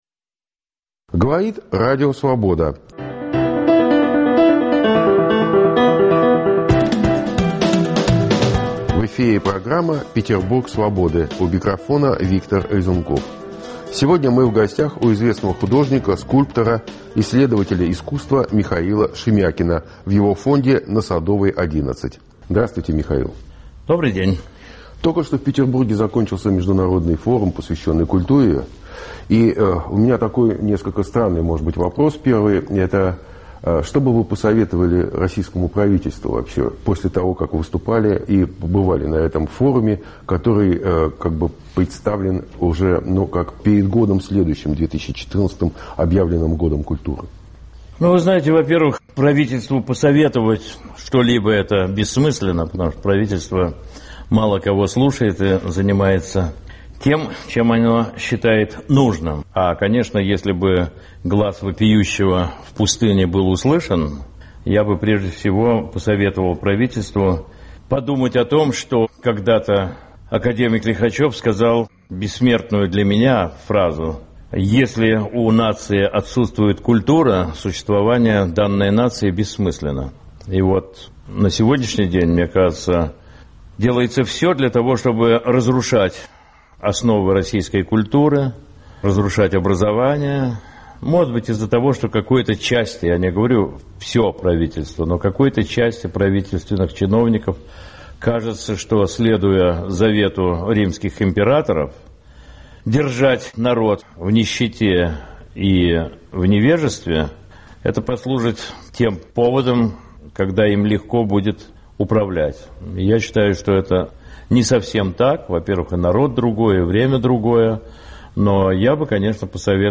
Обо всем этом в беседе с художником, исследователем искусства Михаилом Шемякиным.